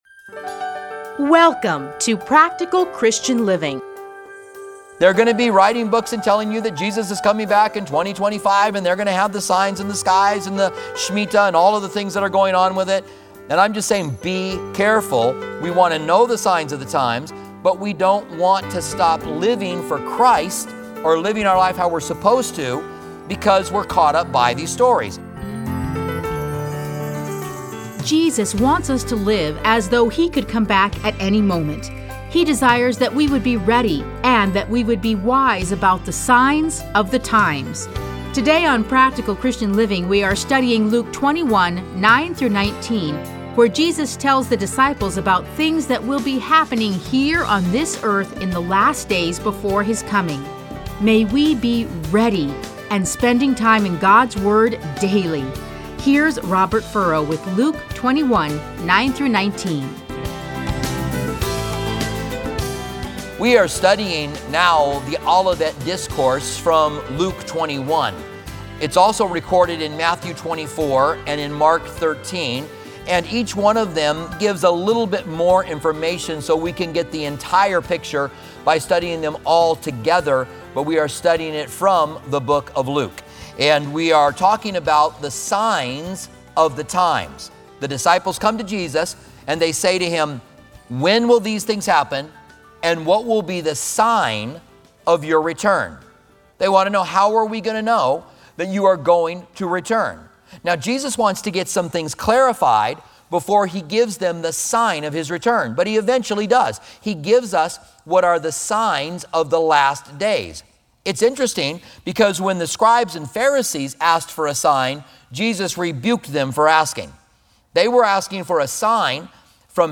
Listen to a teaching from Luke 21:9-19.